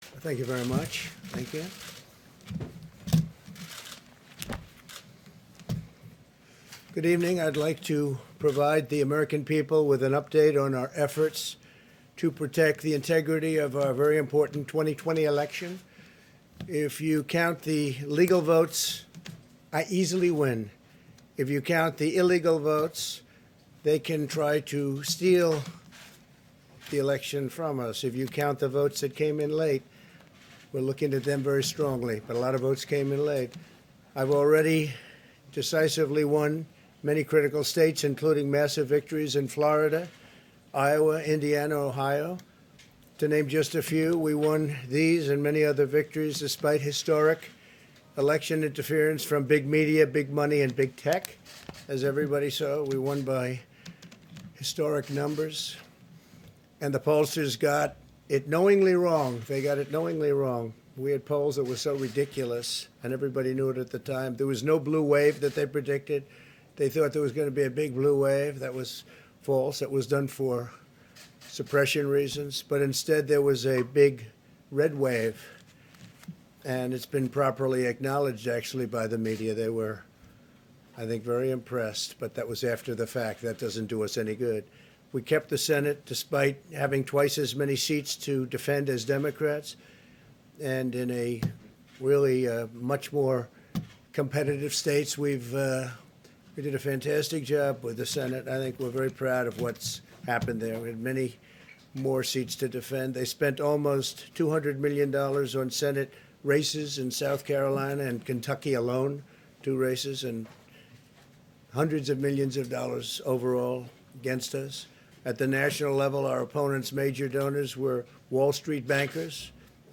Donald_Trump_voice.ogg